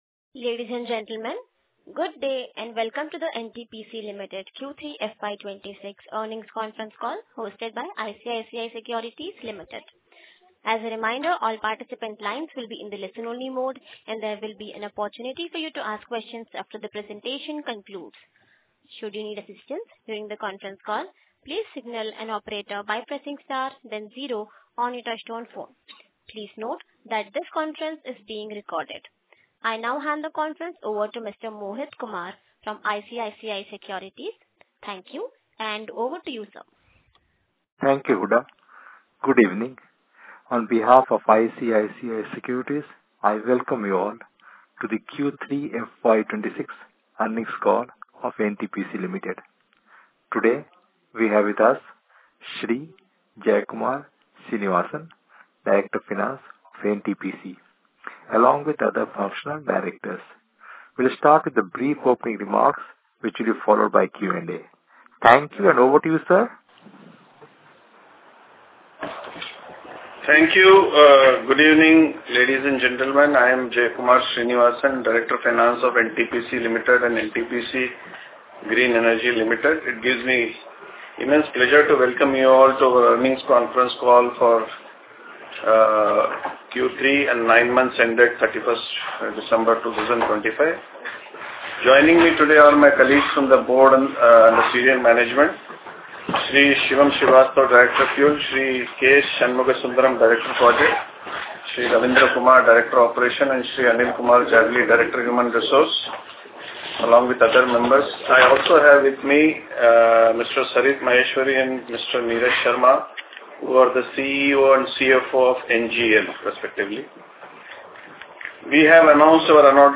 Conference call with Analysts & Investors | NTPC Limited